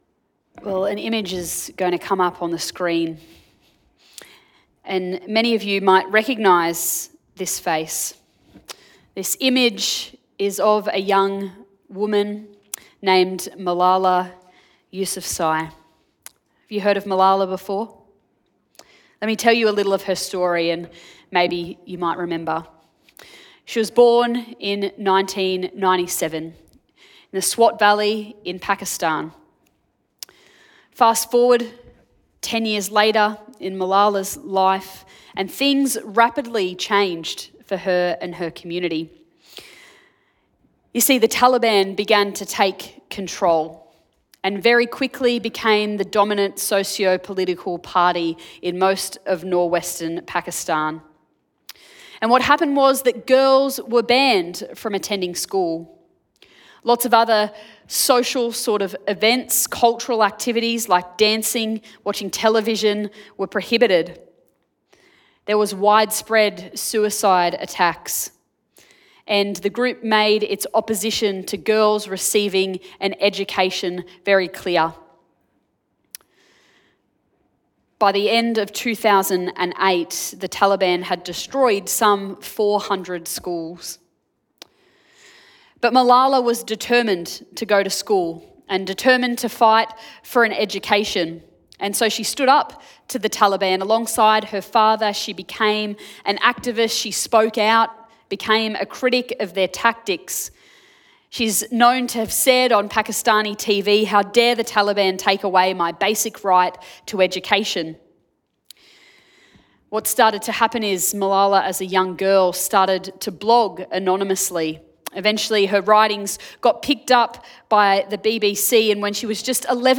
Sermon Podcasts Jesus & Justice